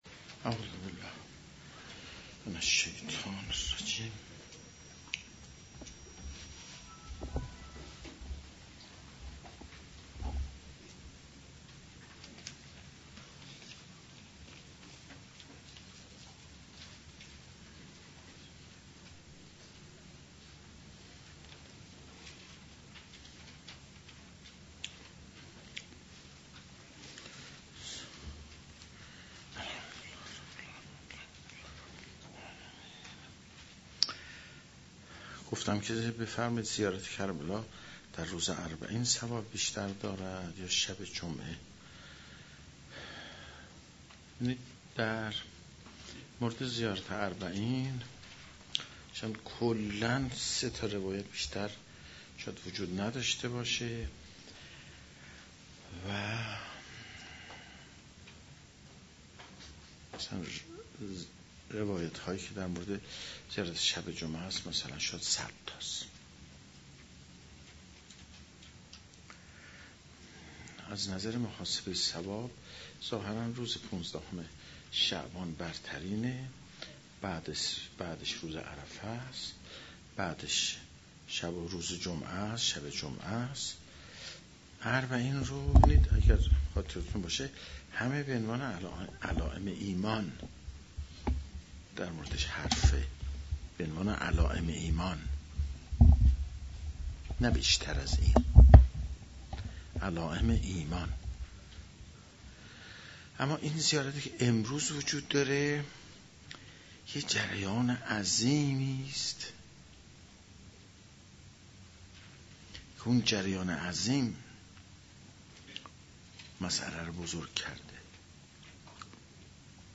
در این جلسه استاد ضمن تدریس در باره سوره الرحمن بیاناتی داشتند که گزیده ای از آن بدین شرح است :